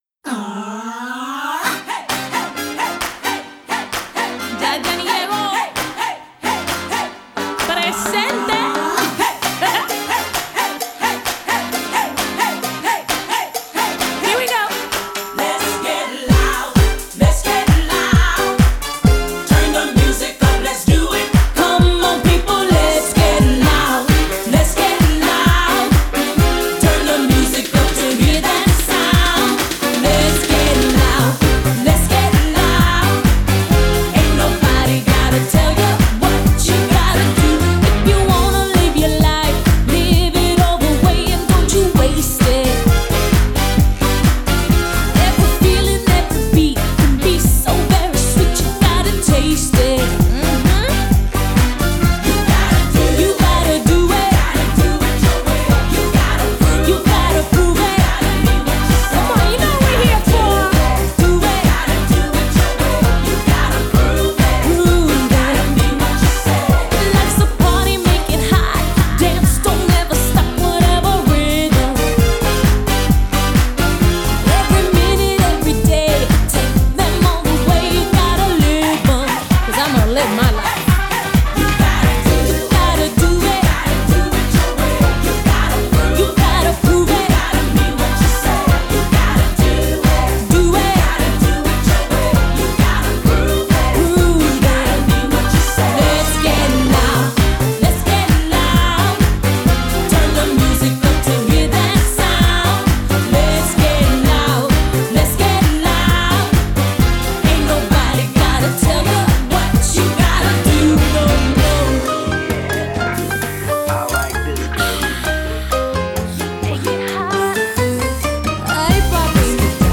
Pop 90er